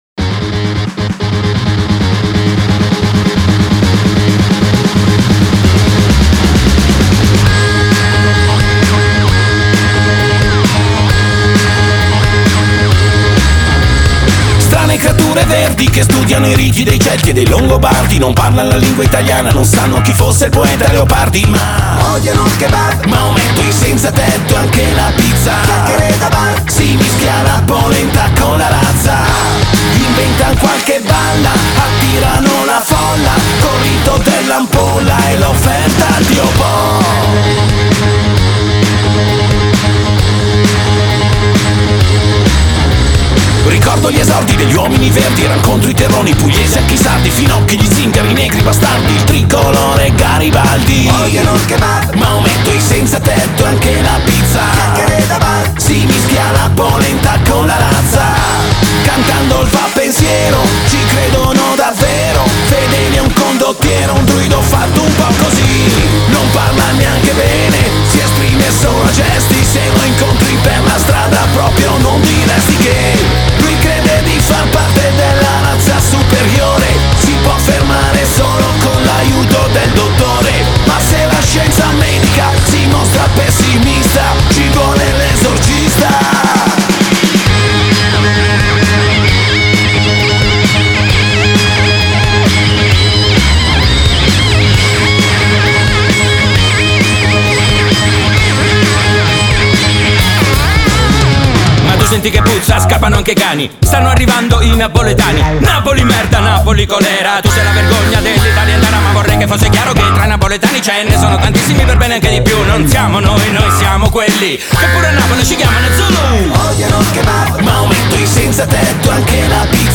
Genre: Rock, Alternative, Punk-Ska